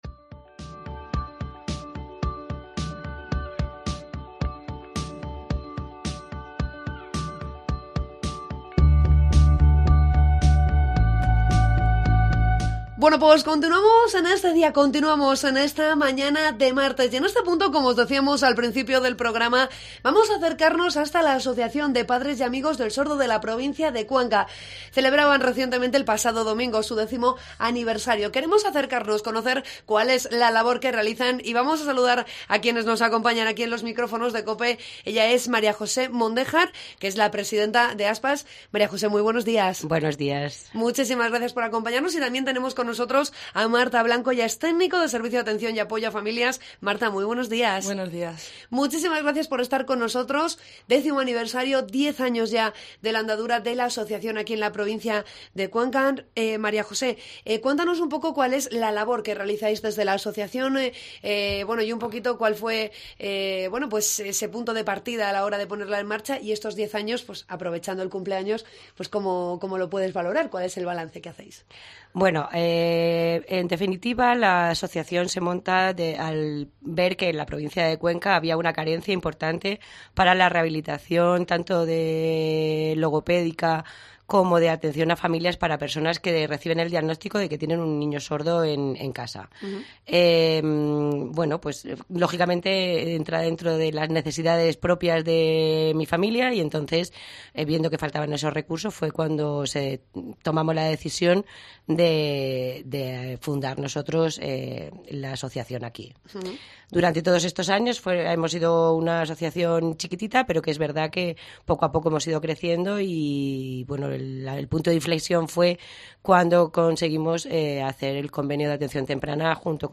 Entrevista con ASPAS con motivo de su décimo aniversario